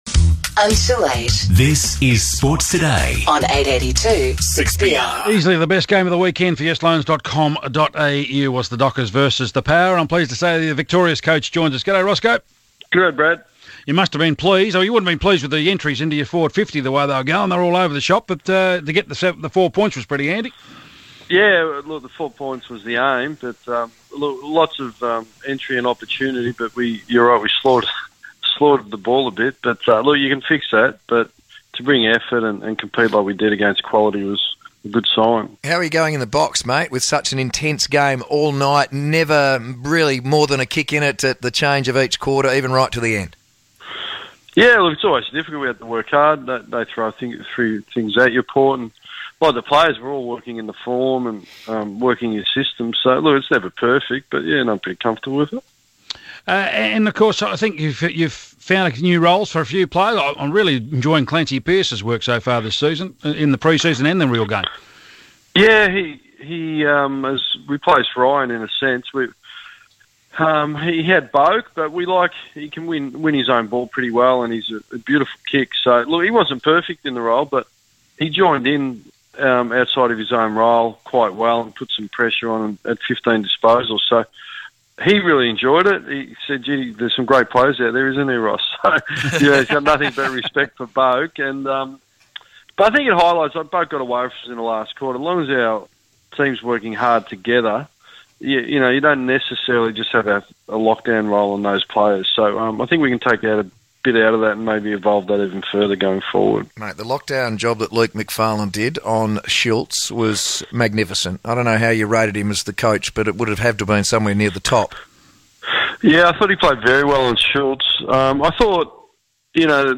The Fremantle Coach speaks to Sports Today about the win over Port Adelaide.